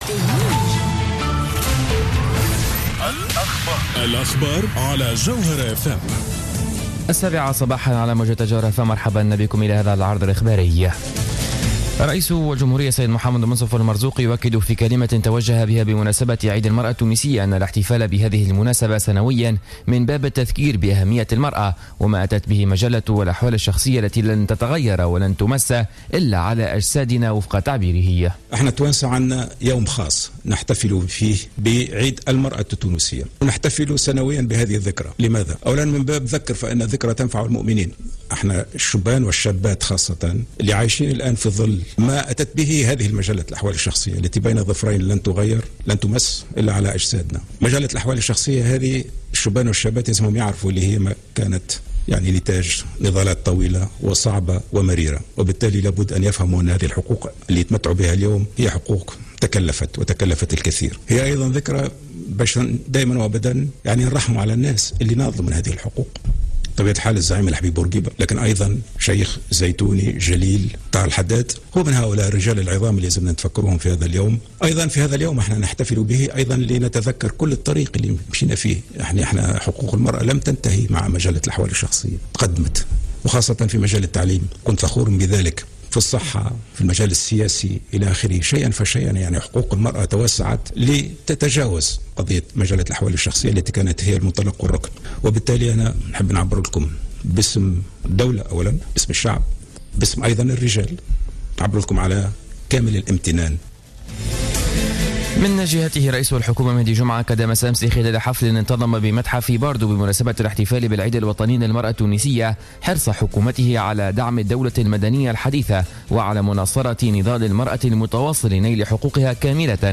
نشرة أخبار السابعة صباحا ليوم الاربعاء 13-08-14